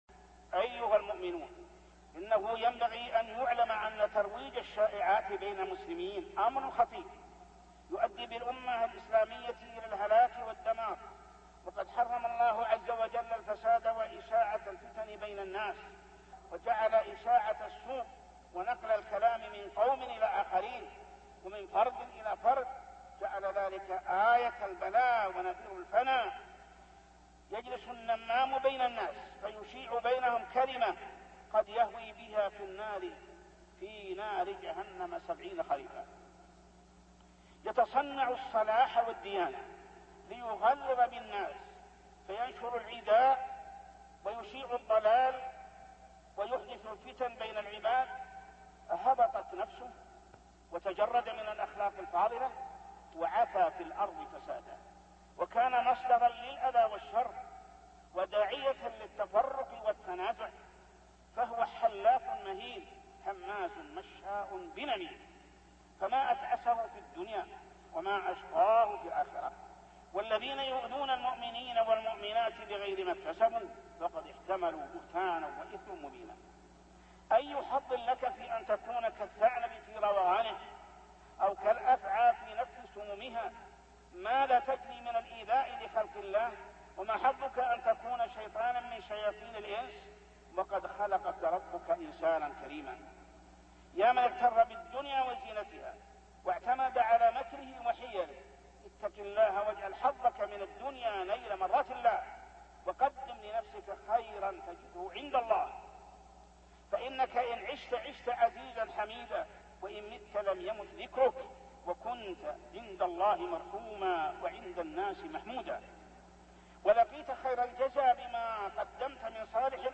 من مواعظ أهل العلم